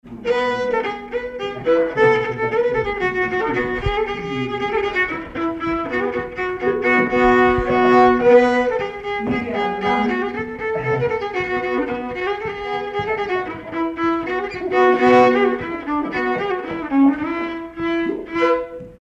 circonstance : bal, dancerie
Pièce musicale inédite